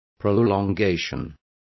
Complete with pronunciation of the translation of prolongation.